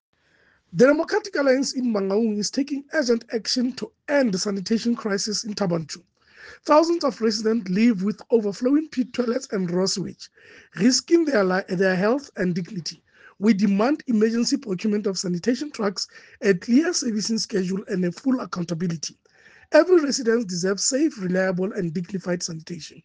English and Sesotho soundbites by Cllr Kabelo Moreeng.